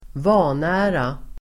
Uttal: [²v'a:nä:ra]